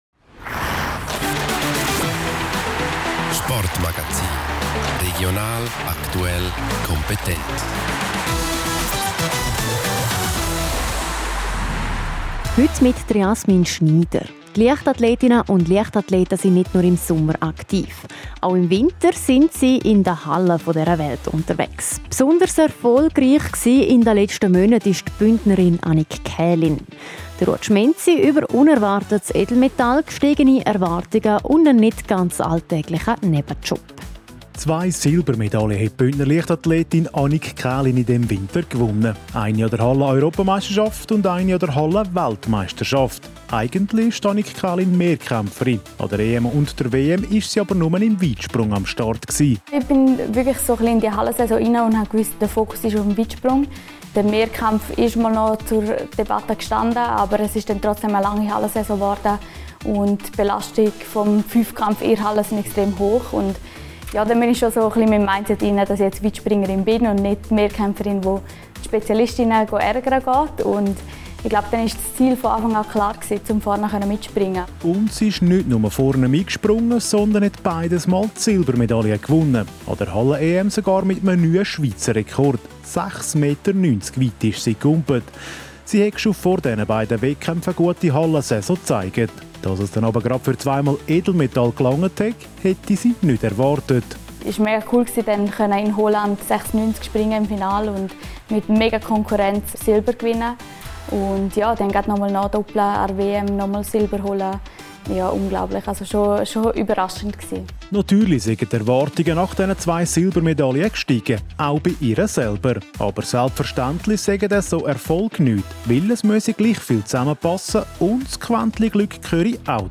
Sport Magazin
Die Bündner Leichtathletin Annik Kälin blickt auf einen erfolgreichen Winter im Weitsprung zurück. Wie sie diese Erfolge einordnet und was sie dabei erlebt hat, erzählt sie im Sportmagazin.